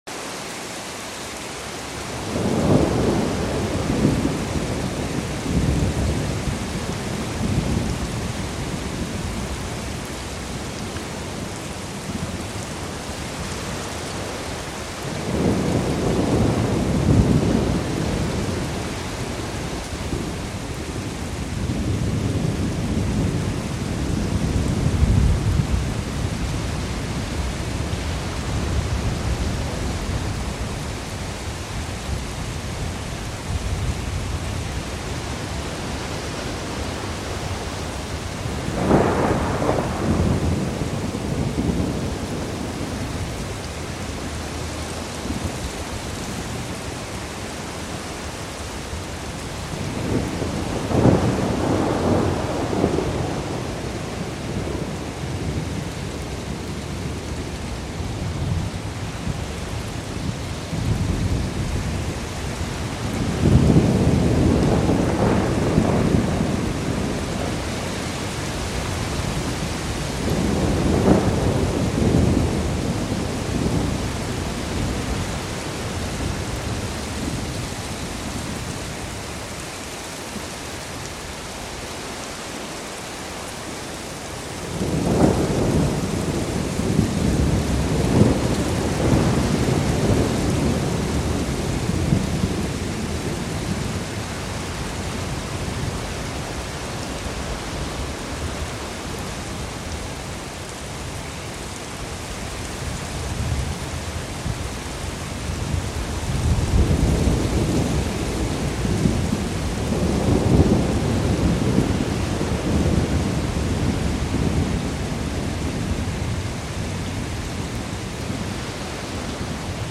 Sleepy Ocean Rainstrom and Thunder sound effects free download
Sleepy Ocean Rainstrom and Thunder Sounds